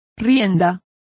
Complete with pronunciation of the translation of reins.